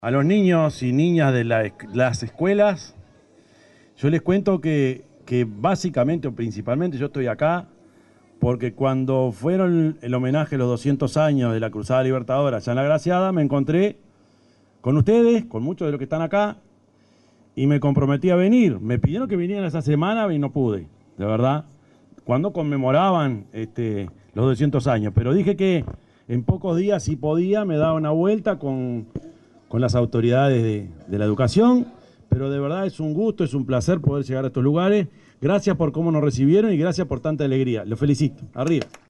Palabras del presidente Yamandú Orsi en la escuela n.° 10 de Mercedes
Palabras del presidente Yamandú Orsi en la escuela n.° 10 de Mercedes 06/05/2025 Compartir Facebook X Copiar enlace WhatsApp LinkedIn En el marco de la visita a la escuela n.° 10 en la ciudad de Mercedes, departamento de Soriano, se expresó el presidente de la República, profesor Yamandú Orsi.